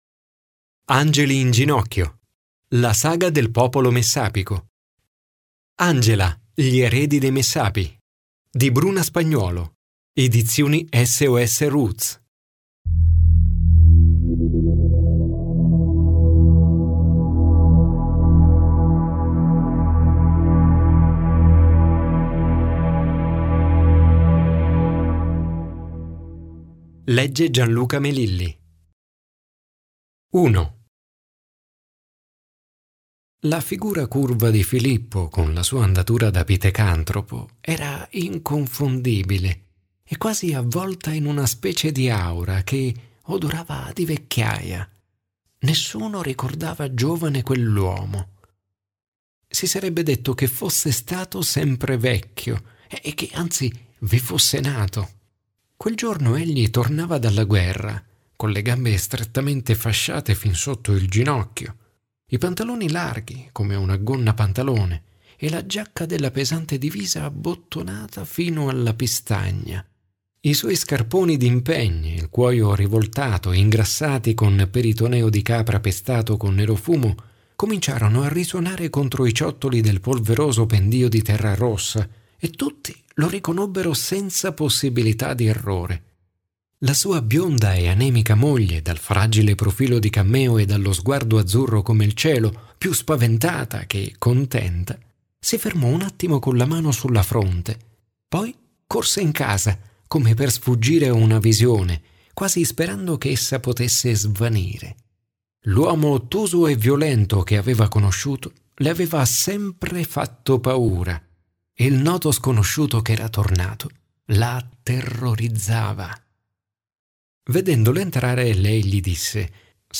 Scarica l'anteprima del libro Leggi l'intervista a Bruna Spagnuolo Ascolta l'incipit del romanzo